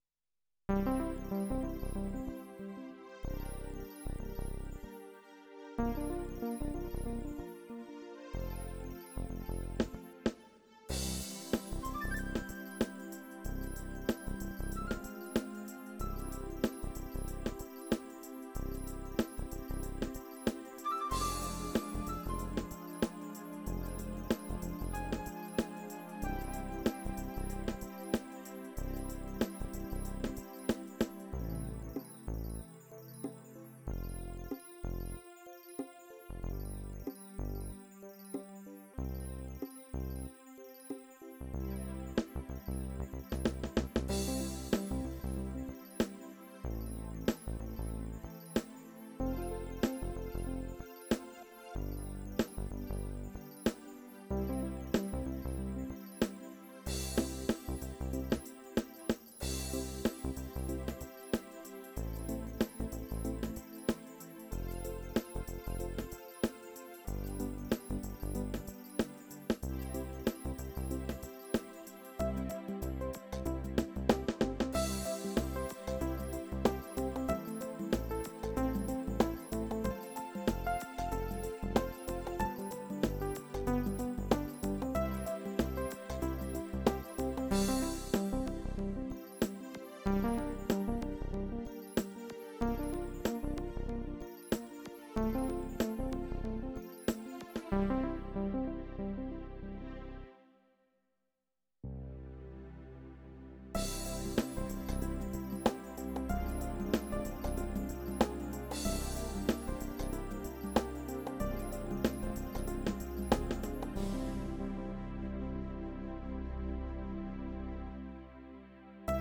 groundbeat